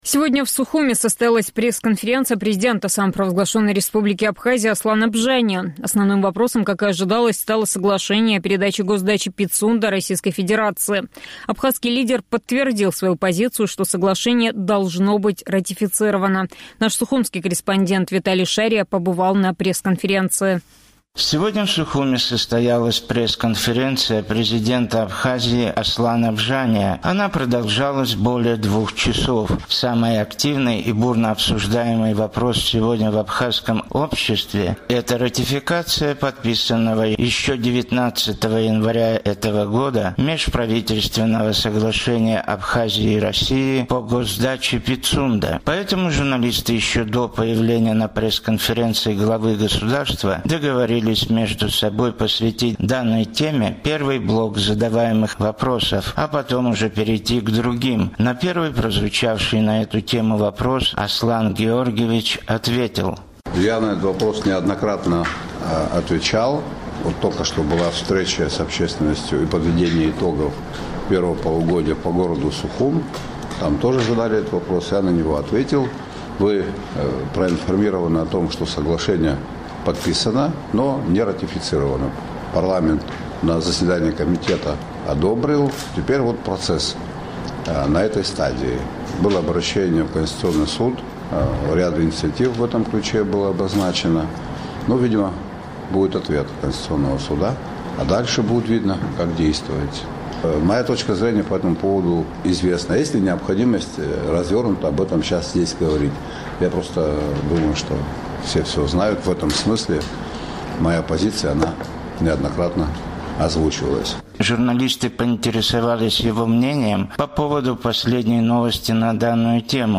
Сегодня в Сухуме состоялась пресс-конференции президента Абхазии Аслана Бжания.